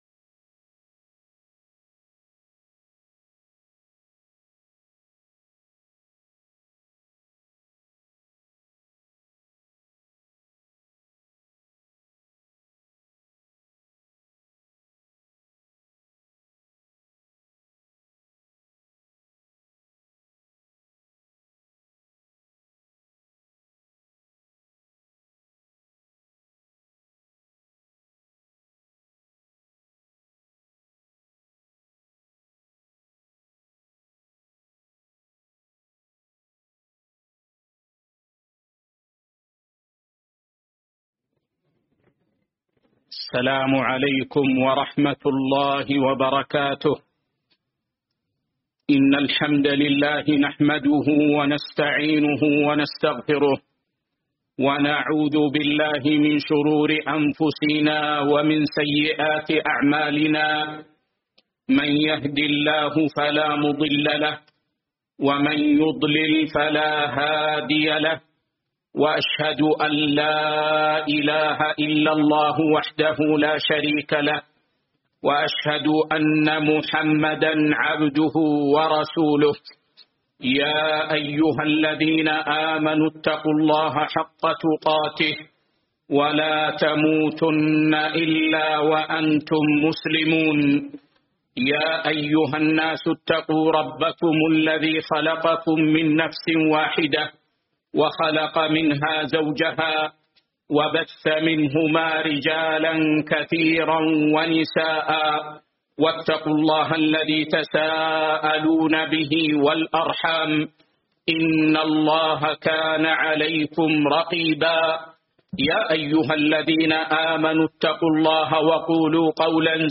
شرح دليل الطالب لنيل المطالب الدرس 310 كتاب الفرائض (1) أول كتاب الفرائض